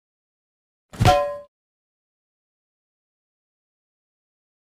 Pan Slap Sound Effect Free Download
Pan Slap